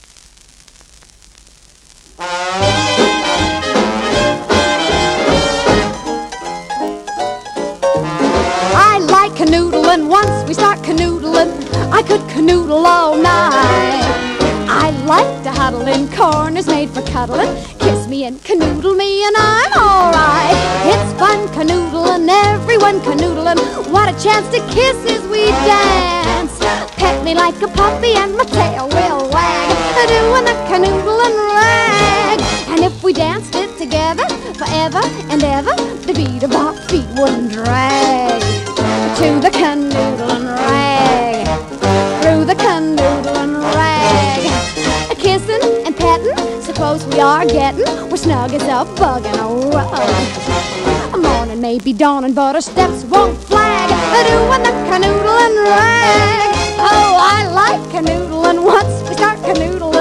このレコードは1956年録音で当時はEP盤(45rpm)との併売されていた様です。